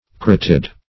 Carotid - definition of Carotid - synonyms, pronunciation, spelling from Free Dictionary
Carotid \Ca*rot"id\, Carotidal \Ca*rot"id*al\, a. (Anat.)